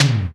NY LOW TOMS.wav